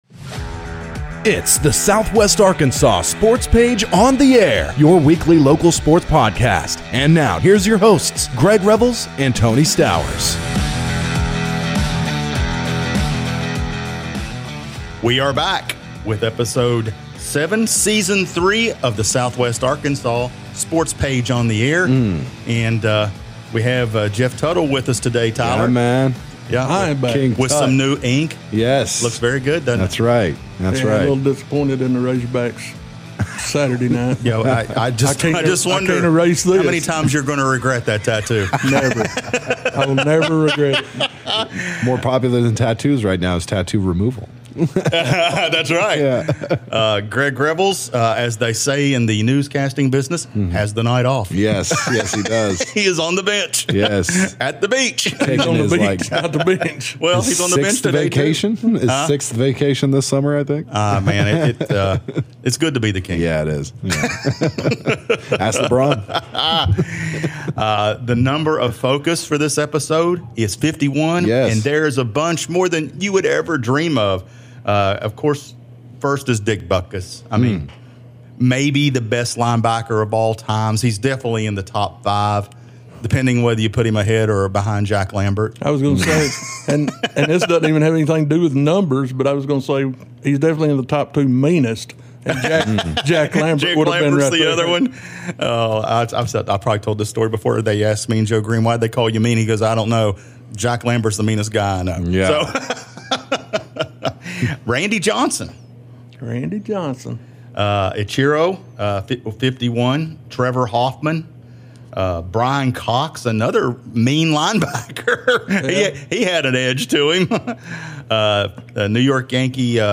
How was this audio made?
calls in to the show.